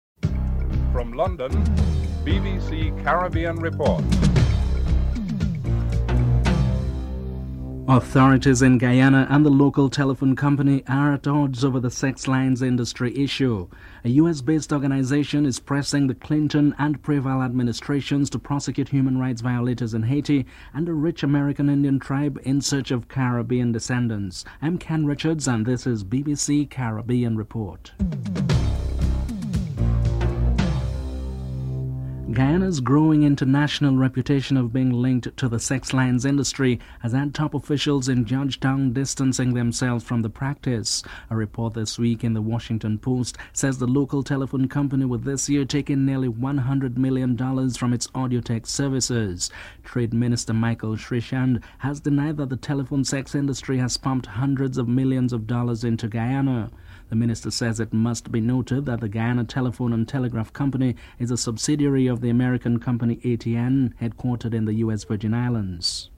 2. Authorities in Guyana and the local telephone company are at odds over the sex lines industry issue.